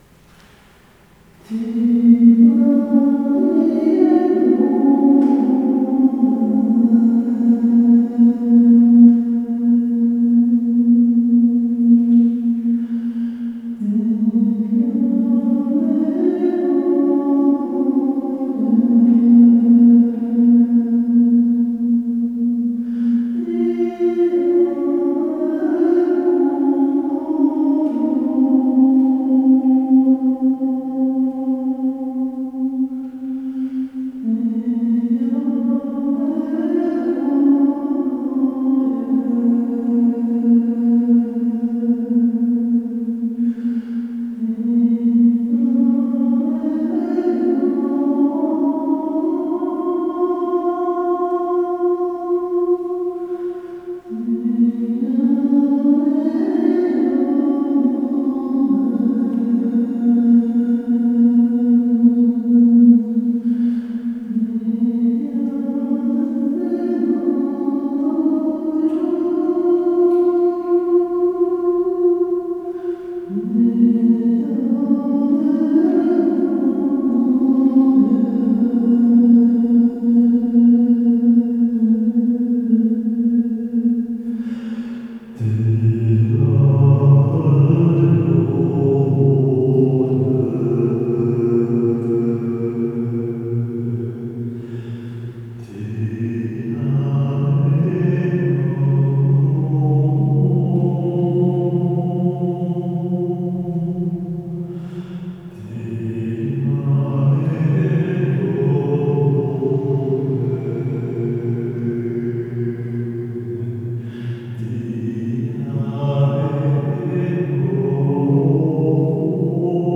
Découvre la vibration particulière pour amener à plus de fluidité.
Laisse toi glisser dans le son, en l’accompagnant d’une une respiration plus profonde et régulière (même temps d’inspiration que d’expiration)